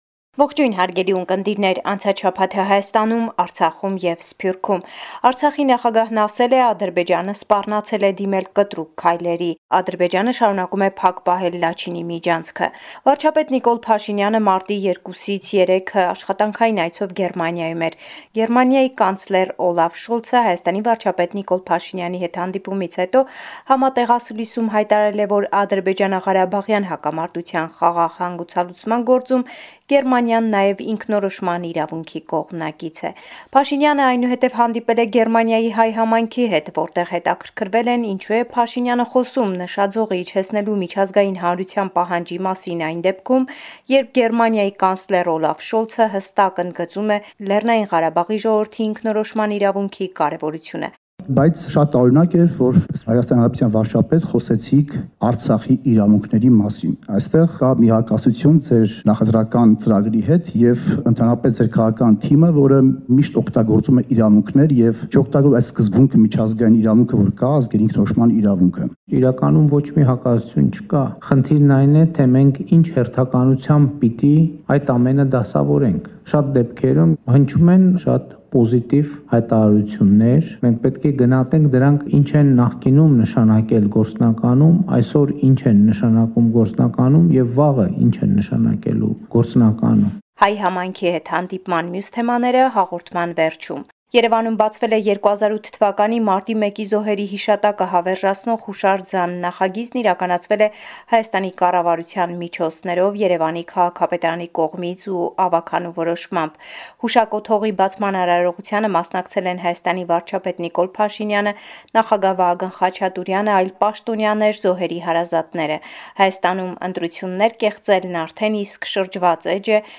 Latest News from Armenia – 7 March 2023